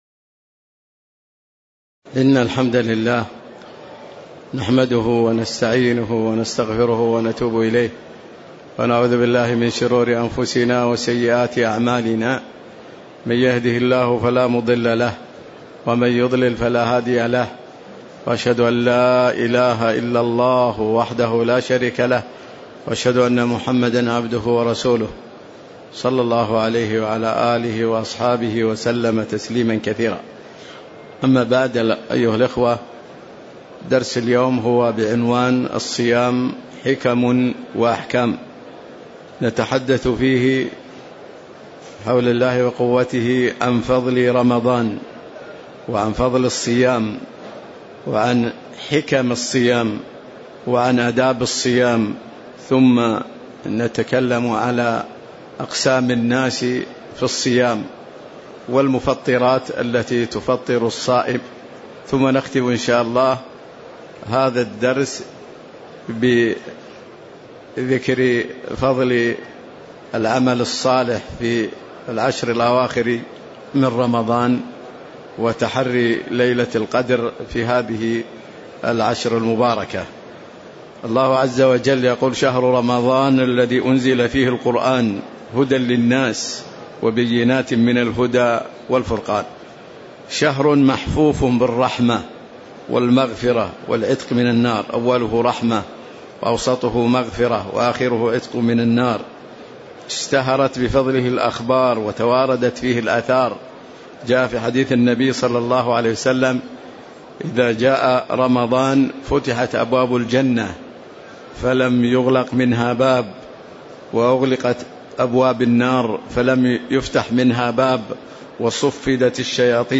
تاريخ النشر ١٧ رمضان ١٤٤٠ هـ المكان: المسجد النبوي الشيخ